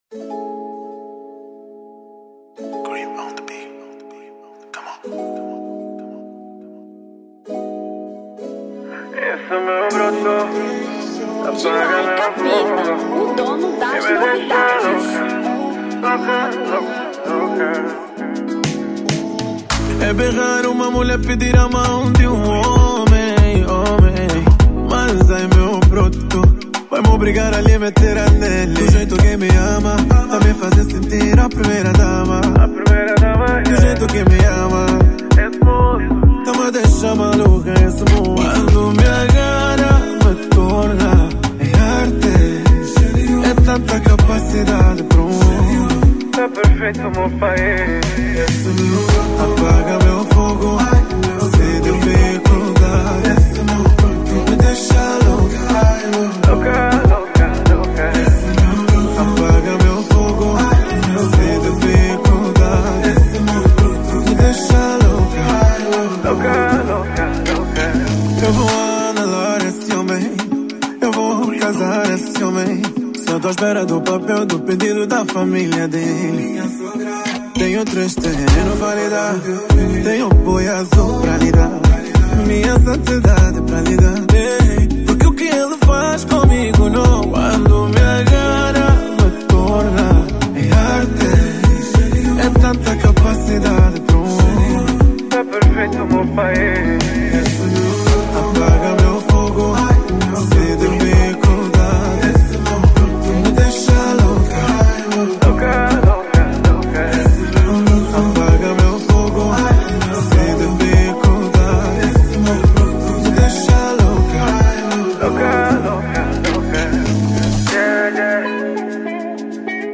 Zouk 2025